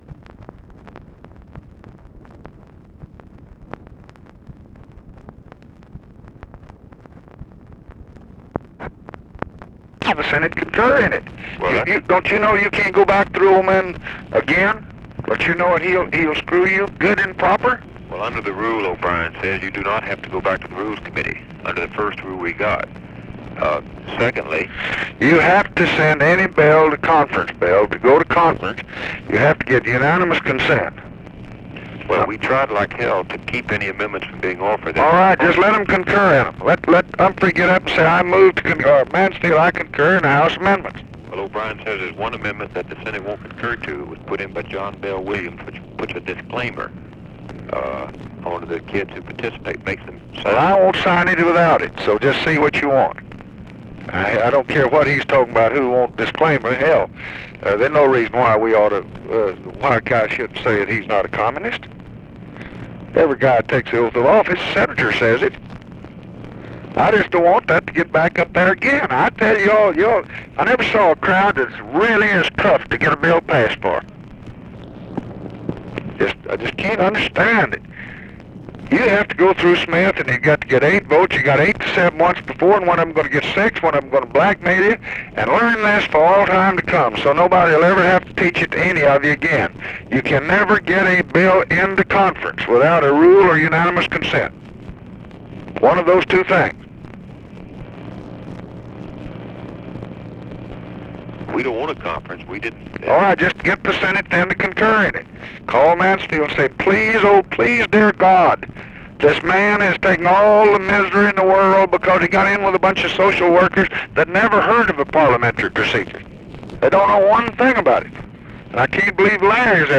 Conversation with WALTER JENKINS and BILL MOYERS, August 8, 1964
Secret White House Tapes